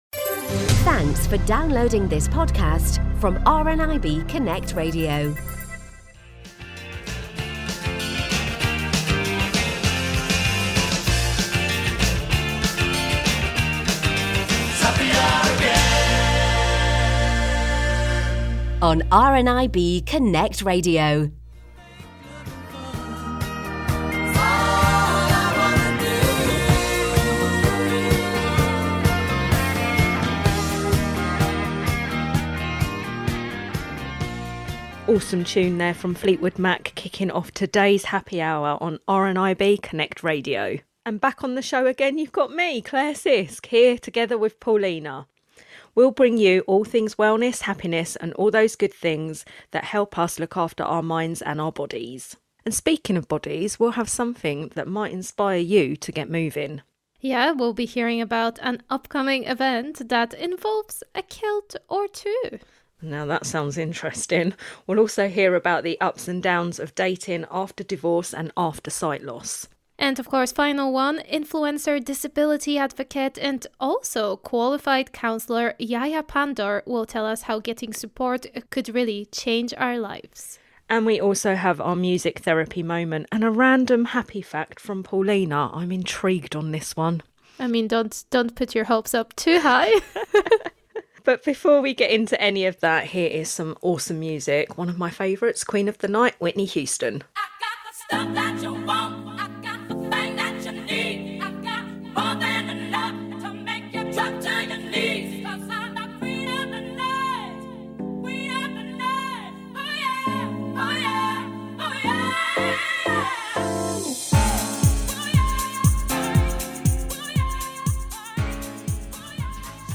The Happy Hour Podcast is our trimmed down version of this hour-long show, so if you'd like to listen to the full show with all the amazing songs featured, catch a new episode of the Happy Hour on RNIB Connect Radio Mondays at 1 PM, with a repeat Thursday at 6 PM and Sunday at 10 am.